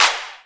Clap 8.wav